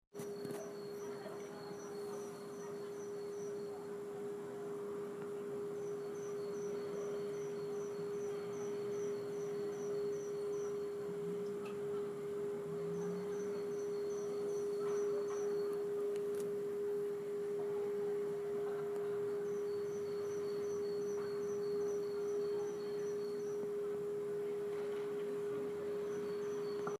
There's a persistent whiny noise in the air near us.
It's only mildly annoying in our flat, the sort of noise that bugs you when you can't sleep but doesn't wake you up.
It's hard to record, but here's a stab done with my phone. It's very quiet on the recording.
It's a fairly pure sounding A♭. Pretty sinewavey.
Of course, it could be submarines or hill-spirits but I suspect it's not The Hum, since everyone can hear it and it's not that low.